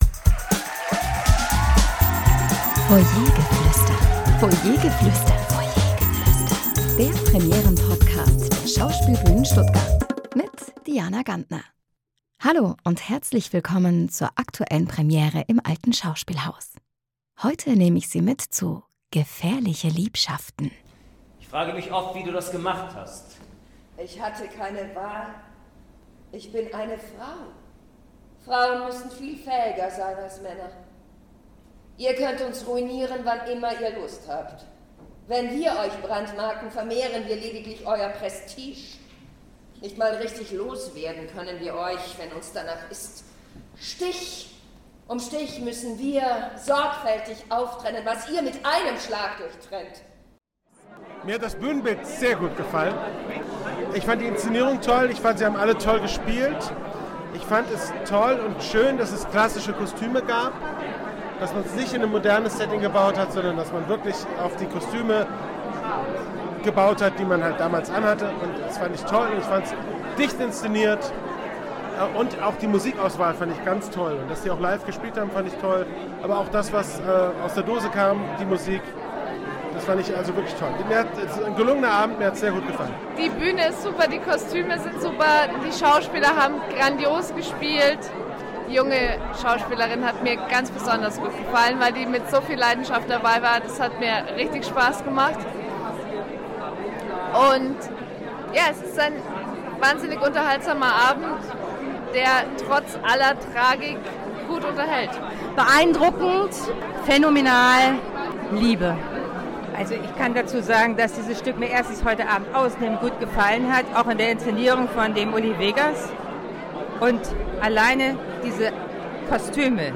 Zuschauerstimmen zur Premiere von “Gefährliche Liebschaften”